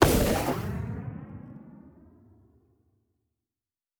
pgs/Assets/Audio/Sci-Fi Sounds/Weapons/Sci Fi Explosion 04.wav at 7452e70b8c5ad2f7daae623e1a952eb18c9caab4
Sci Fi Explosion 04.wav